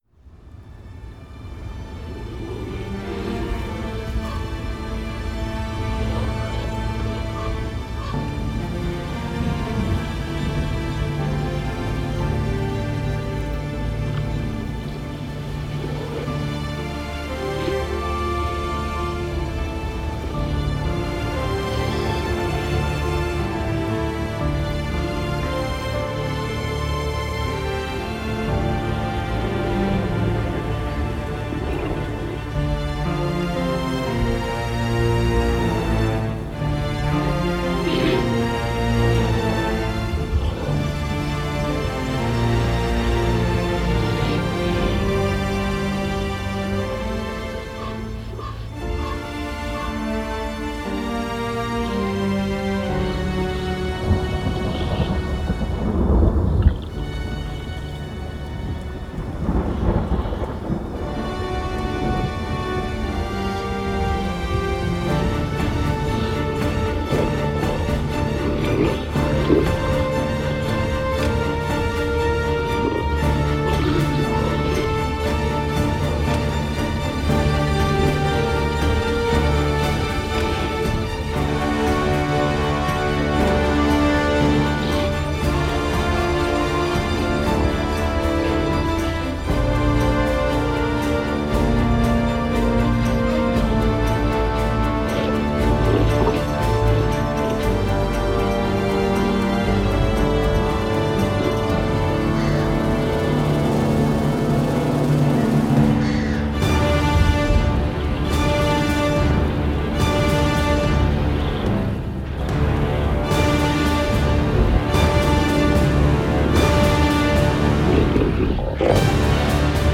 Efeitos Sonoros
Coleção de sons para enriquecer suas sessões de RPG.